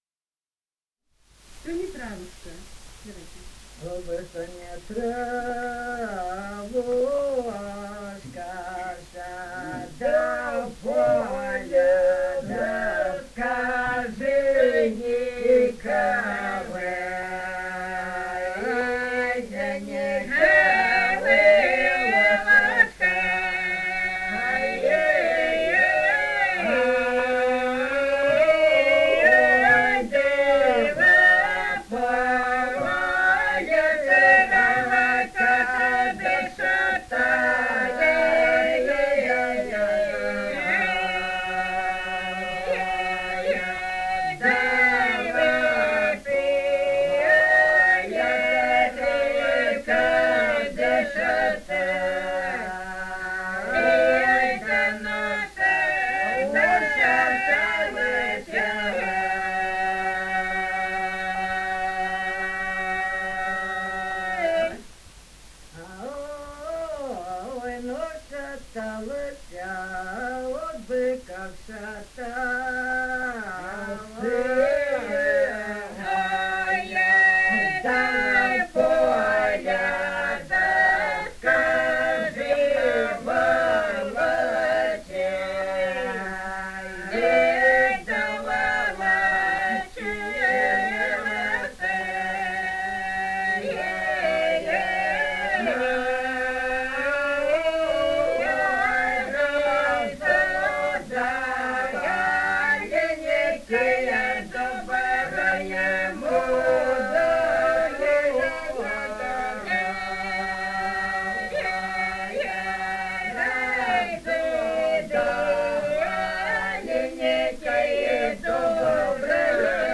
«Ох, бы что не травушка» (лирическая).
Ростовская область, г. Белая Калитва, 1966 г. И0942-09